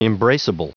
Prononciation du mot embraceable en anglais (fichier audio)
Prononciation du mot : embraceable